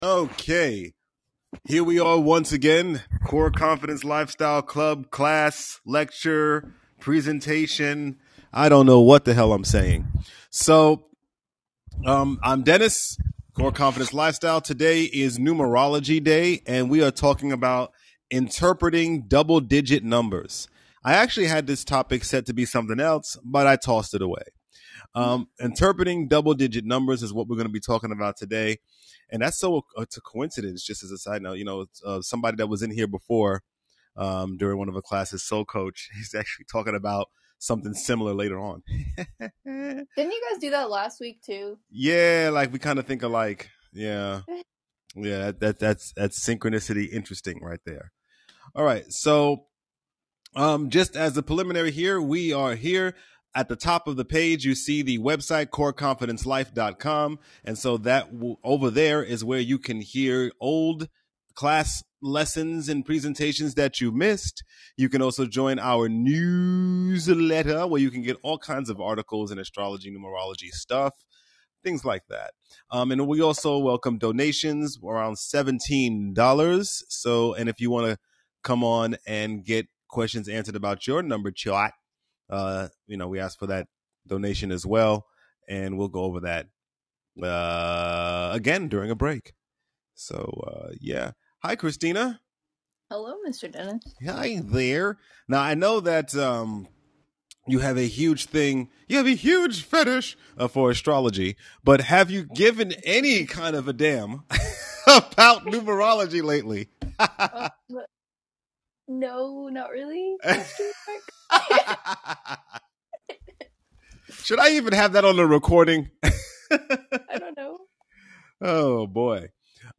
Classroom Replay, Interpreting Multiple Numbers in Numerology